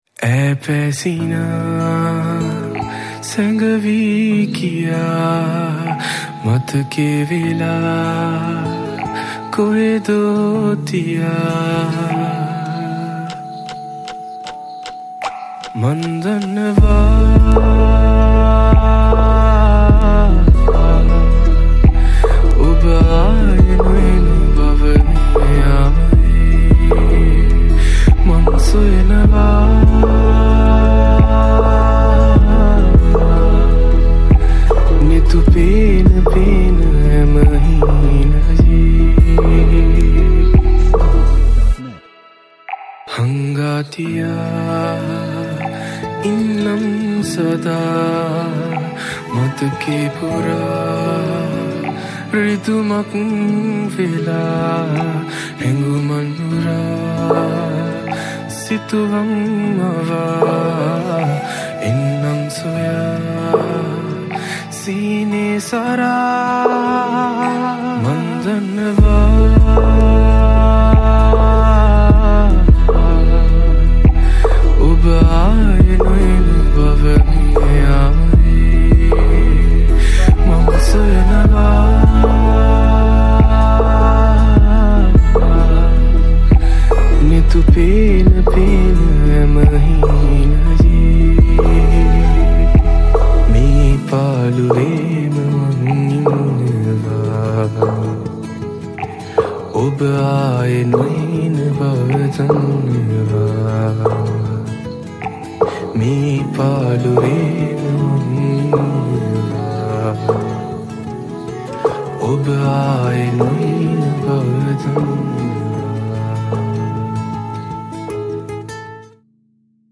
Flutes